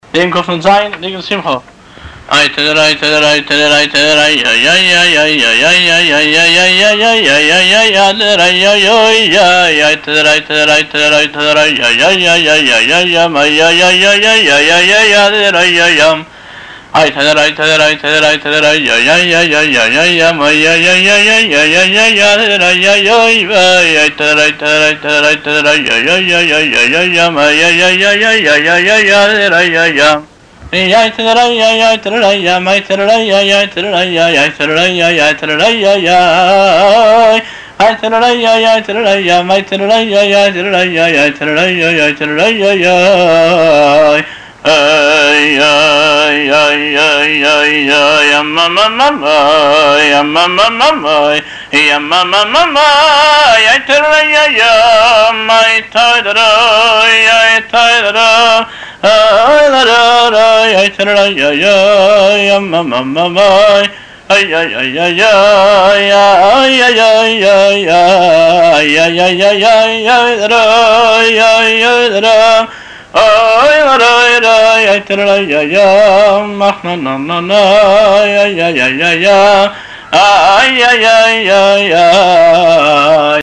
הבעל-מנגן